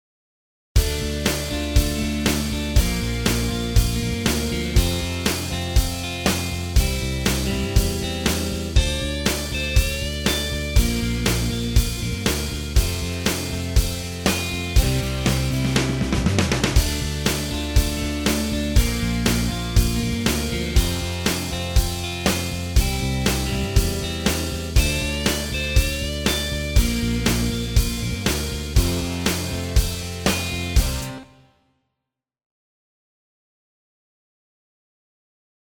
רמז: שיר של מקהלת ילדים בחו"ל